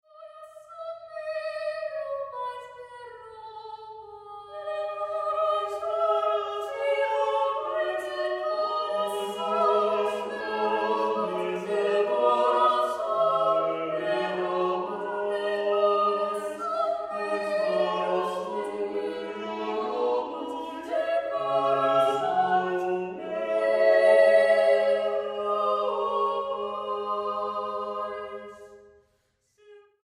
Música Barroca Mexicana